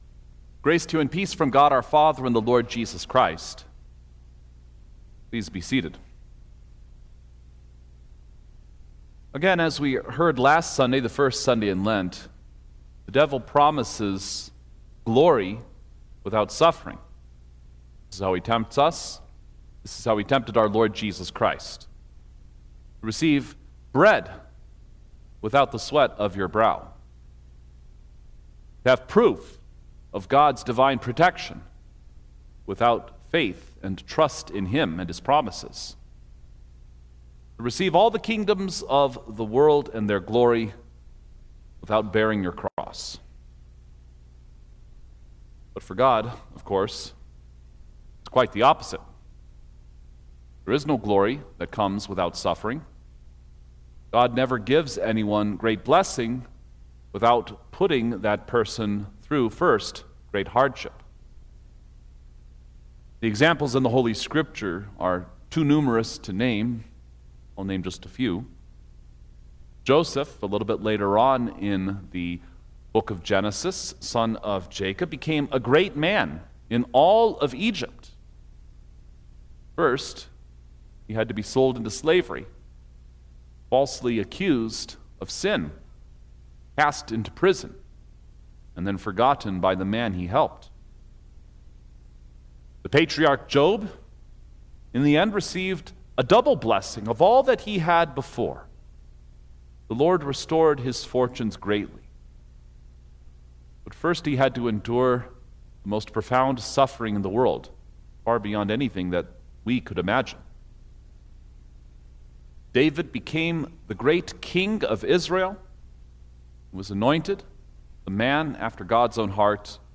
March-1_2026_Second-Sunday-in-Lent_Sermon-Stereo.mp3